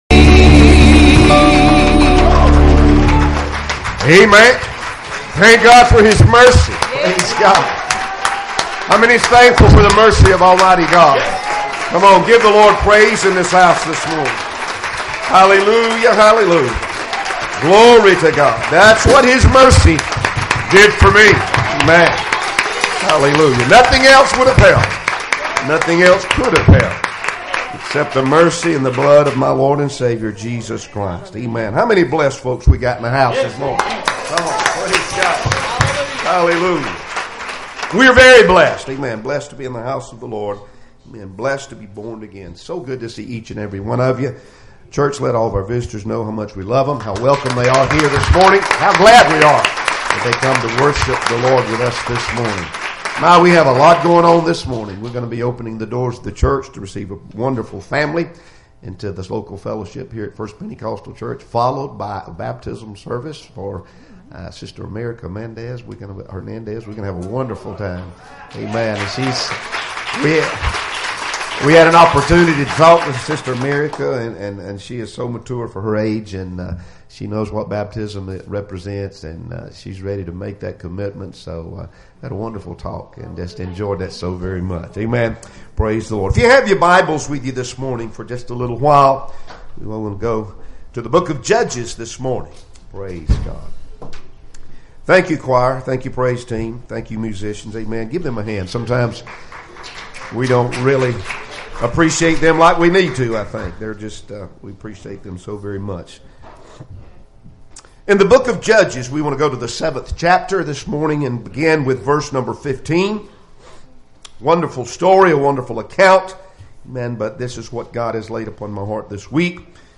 Passage: Judges 7:15-21 Service Type: Sunday Morning Services Topics